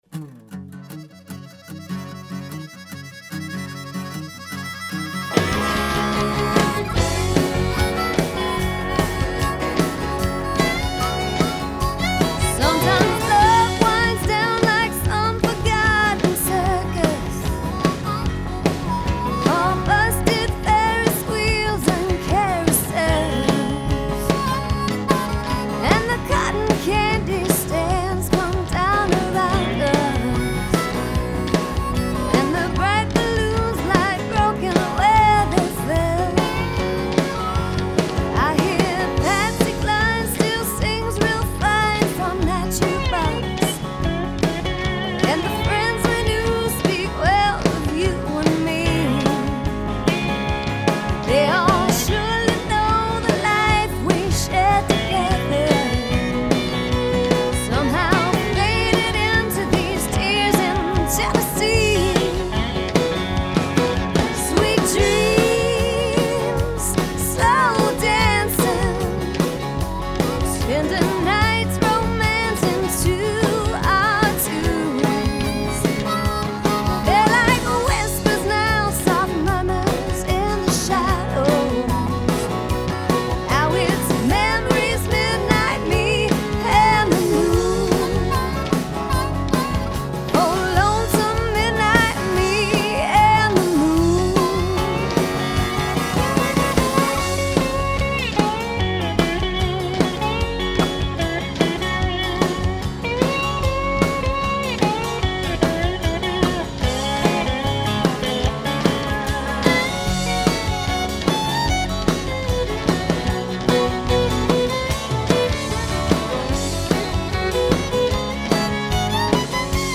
Guitars
Drums
Piano
Fiddle
Harmonica
Bass Guitar